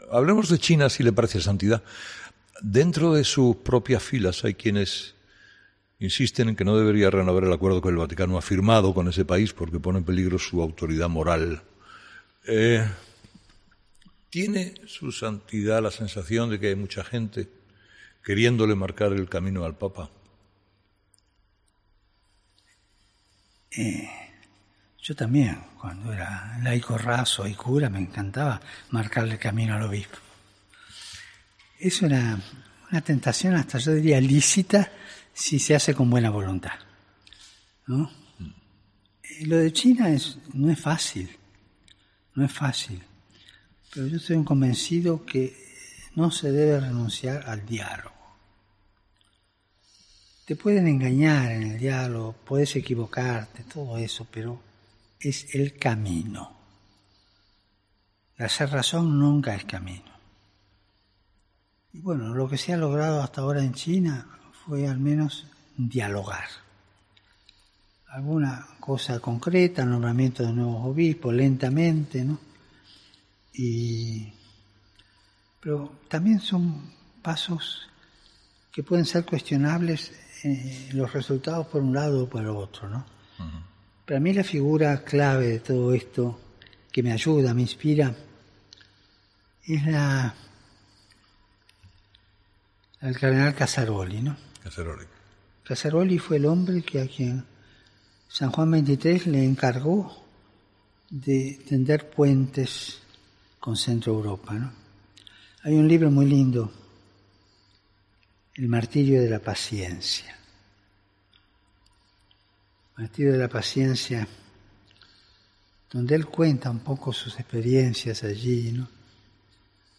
La renovación del acuerdo que el Vaticano firmó con China, con la que determinados sectores no están de acuerdo al considerar que se pone en peligro la autoridad moral del Papa, es una de las preguntas que ha planteado Carlos Herrera al Santo Padre.